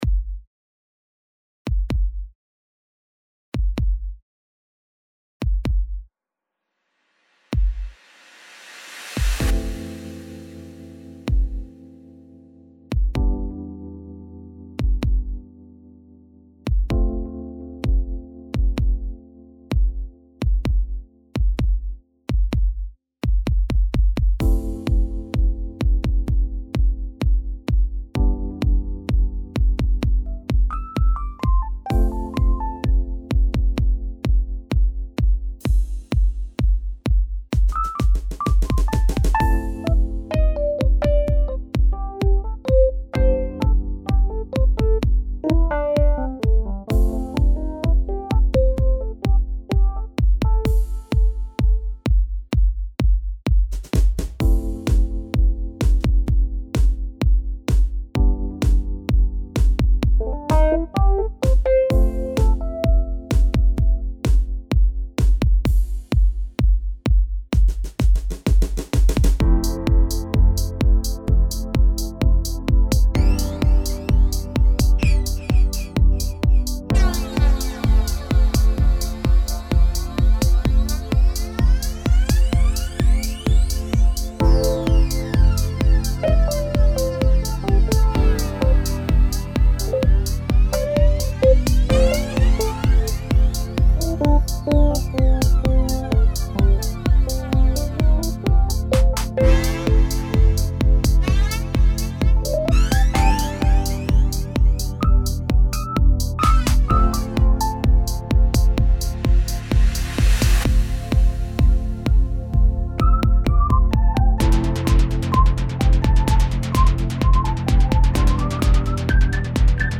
Mein erstes größeres Cubase-Projekt nach langer Pause. Und mein erster Elektroblues.